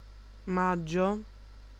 Ääntäminen
Synonyymit maggiore Ääntäminen : IPA: /ˈmad.dʒo/ Haettu sana löytyi näillä lähdekielillä: italia Käännös Ääninäyte Erisnimet 1.